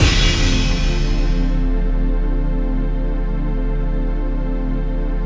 DM PAD5-2.wav